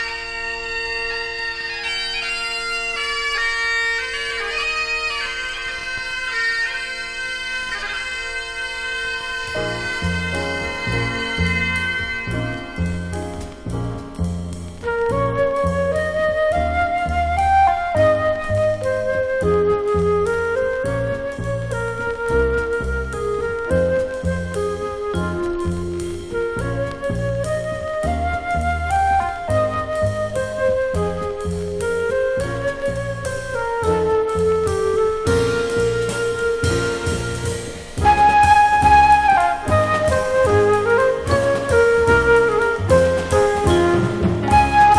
Jazz, Bop, Modal, Cool Jazz　USA　12inchレコード　33rpm　Stereo